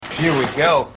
Tags: t pain bud light commercial tpain bud light bud light commercial autotune